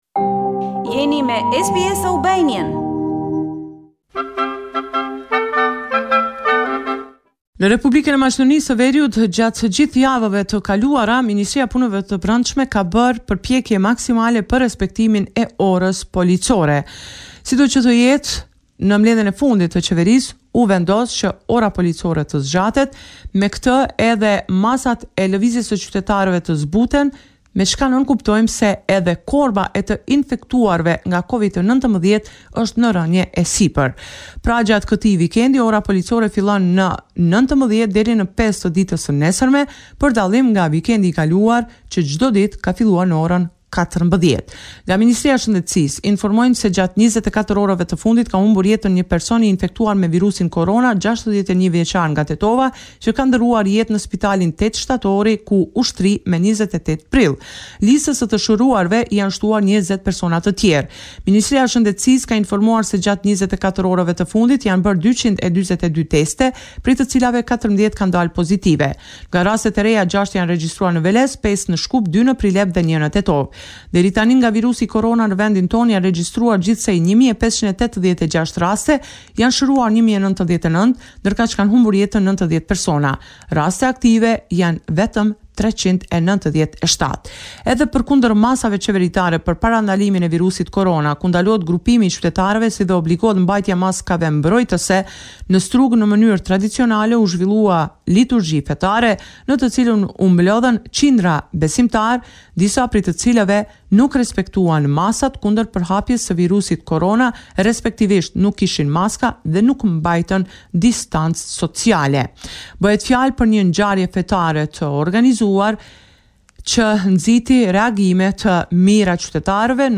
This is a report summarising the latest developments in news and current affairs in